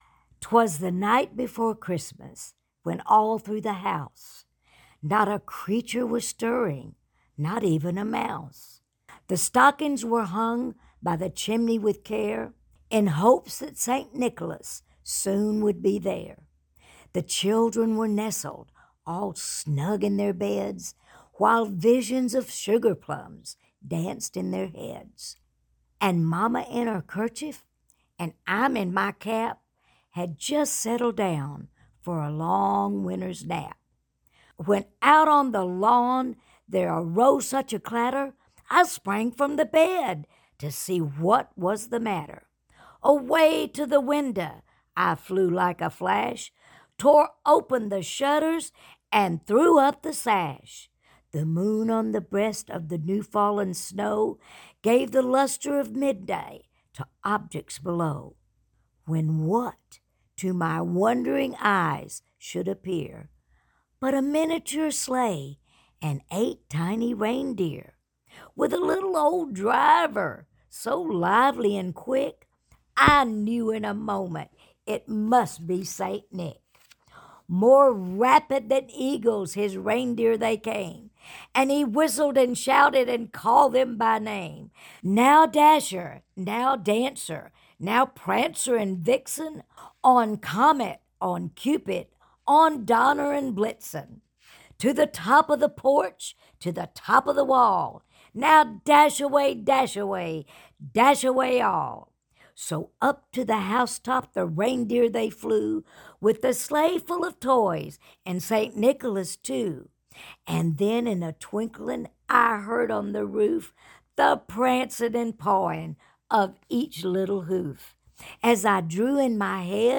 Here’s a solo read of “‘Twas the Night Before Christmas” from Brenda Lee.
Here's a (DRY) read of "'Twas The Night Before Christmas" from the iconic Brenda Lee.
BrendaLee_TwasTheNightBeforeChristmas_Reading.mp3